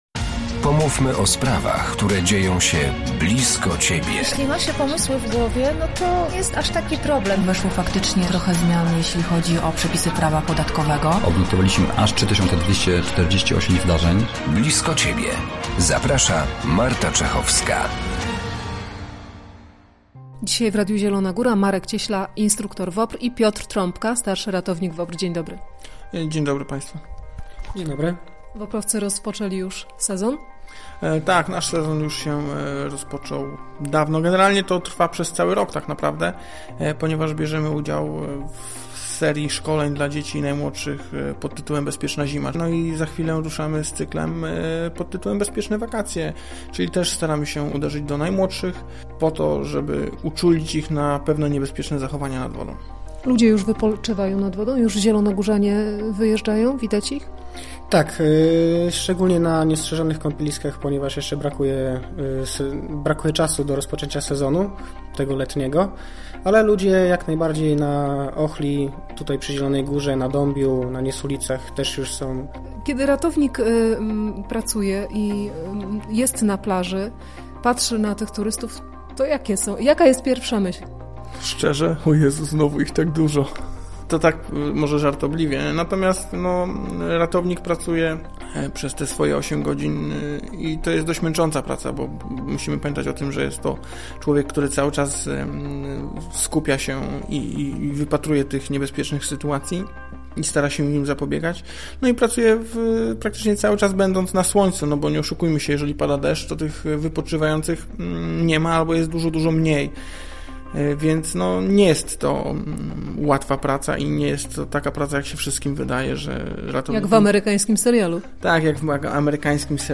Posłuchajcie, co jego przedstawiciele mówią o wypoczynku nad wodą, zachowaniu wypoczywających i…podwodnych znaleziskach: